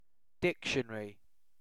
dictionary-uk.mp3